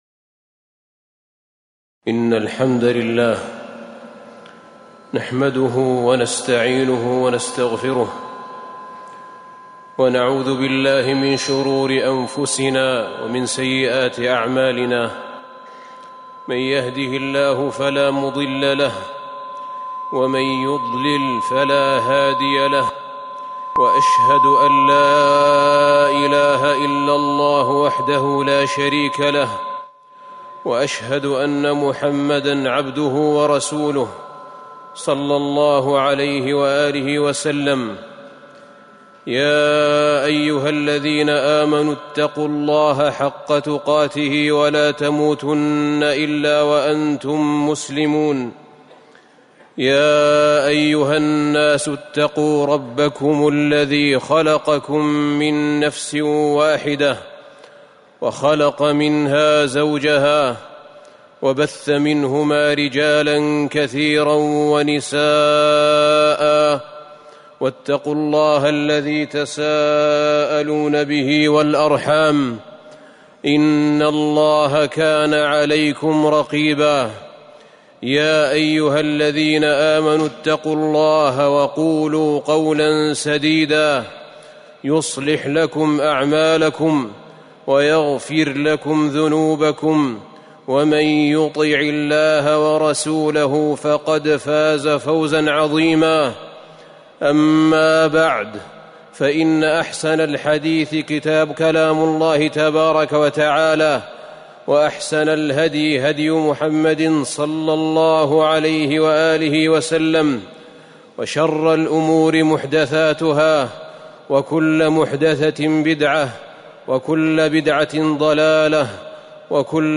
تاريخ النشر ١٦ ربيع الثاني ١٤٤١ المكان: المسجد النبوي الشيخ